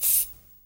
Звуки скунса
Скунс выстреливает вонючей жидкостью эффект